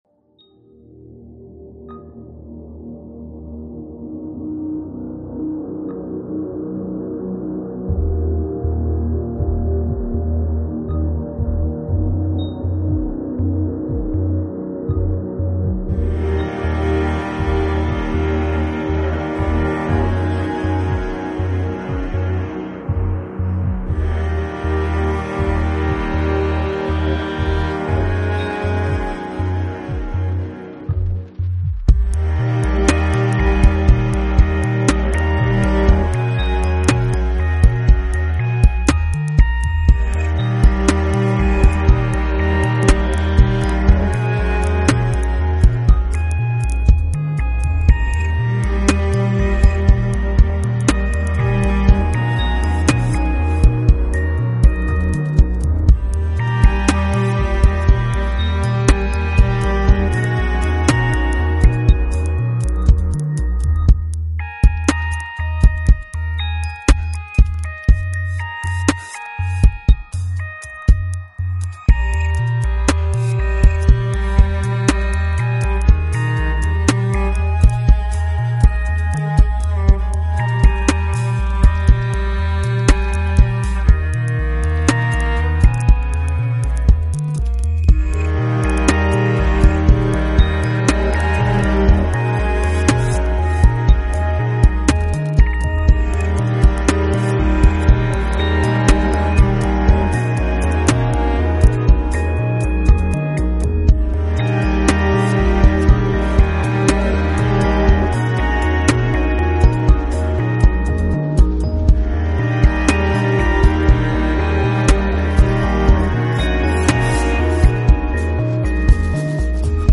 Style: New age│Ambient